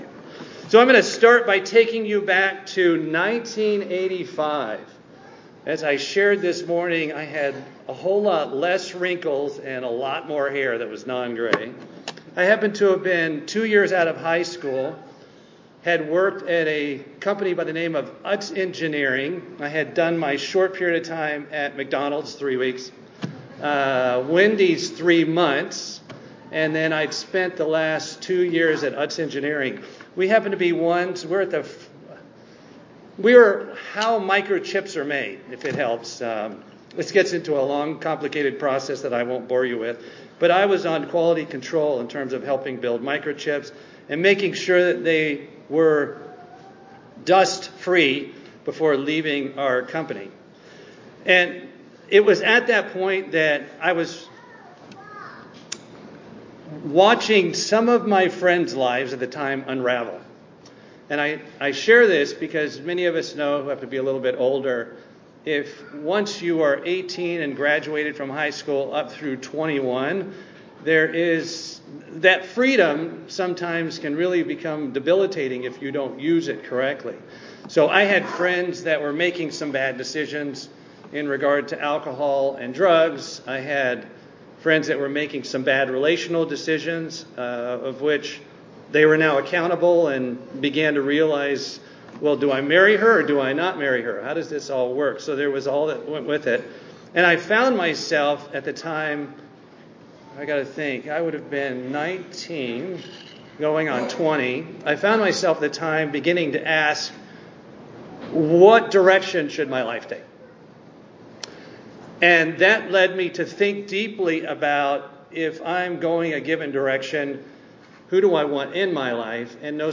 Sermons
Given in Atlanta, GA Buford, GA